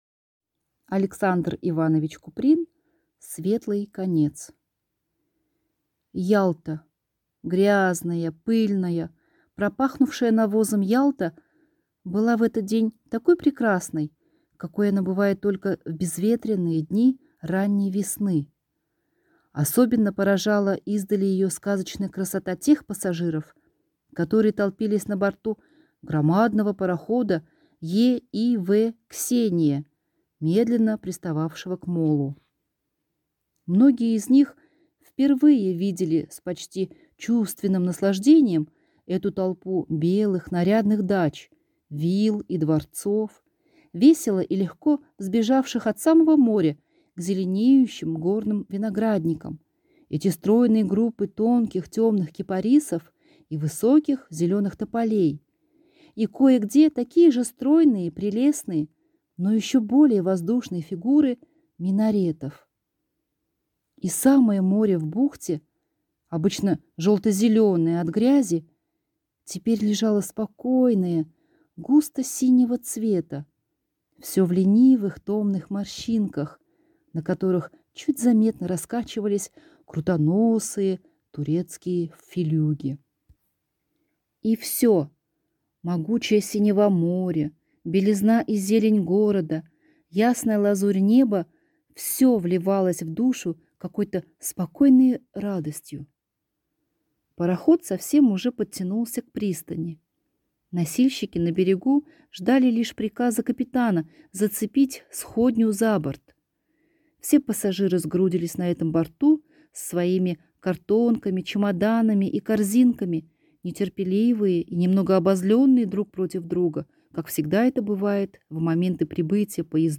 Aудиокнига Светлый конец